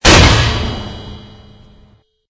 CHQ_FACT_stomper_large.ogg